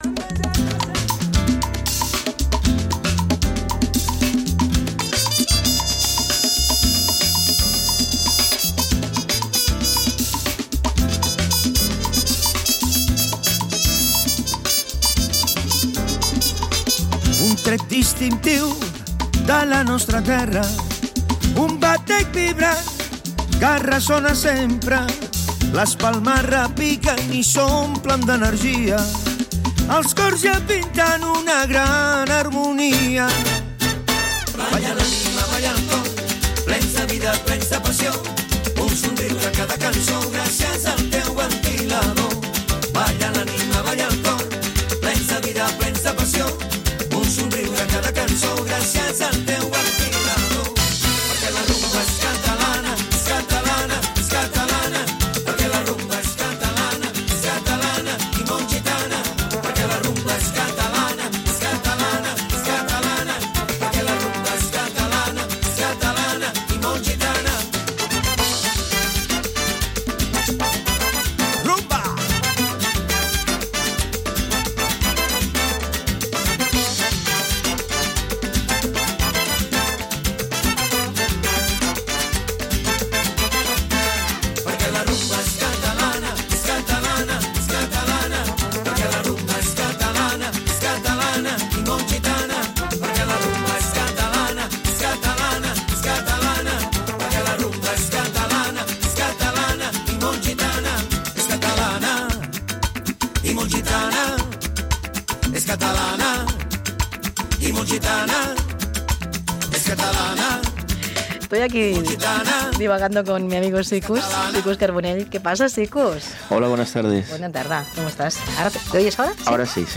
entrev-sabor-de-gracia.mp3